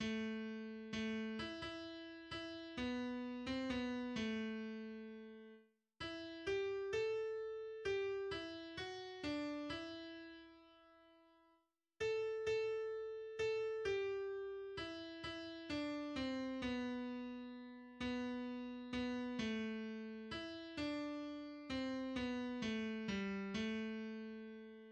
\tempo 4 = 130
\time 3/4
\key c \major
Text & Melodie Volkslied